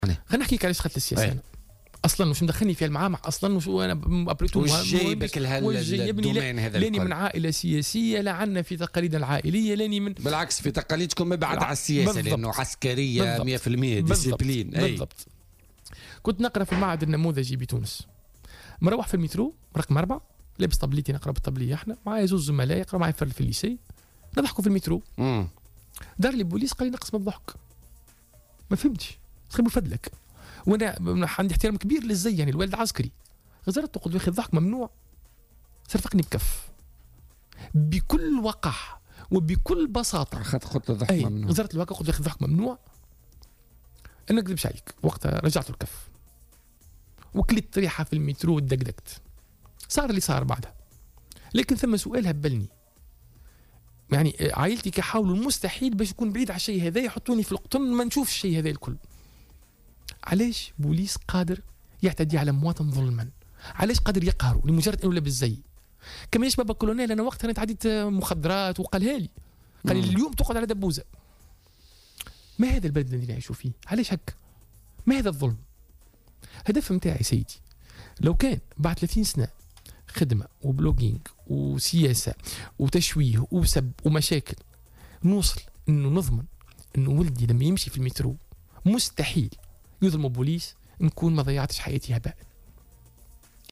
وأضاف في مداخلة له اليوم في برنامج "بوليتيكا" أنه كان رفقة أصدقائه على متن "المترو" عندما تلقى صفعة من رجل أمن "ظلما" وهو ما أثّر فيه كثيرا، وفق تعبيره.